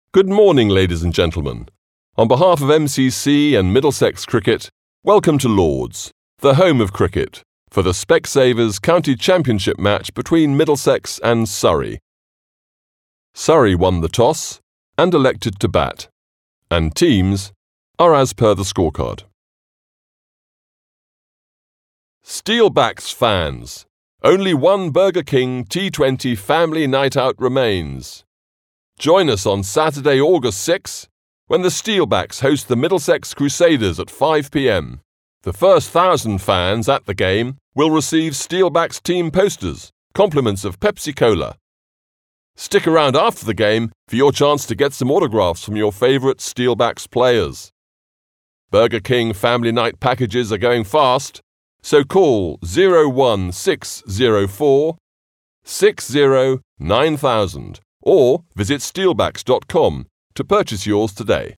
STADIUM ANNOUNCING
STADIUM ANNOUNCEMENT